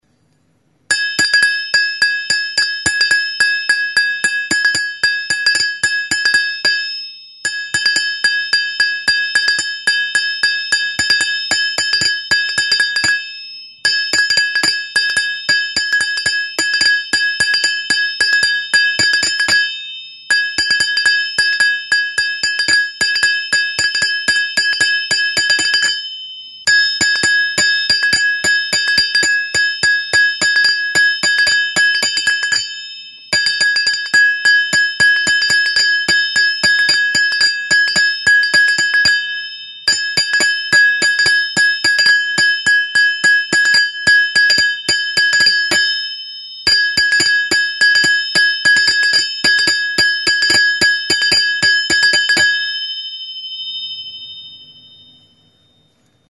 Idiophones -> Struck -> Directly
Almeiza joaldia fandango erritmoan.
Recorded with this music instrument.
Brontzezko almaiza arrunta da. Mazoa ere brontzezkoa da.
METAL; BRONZE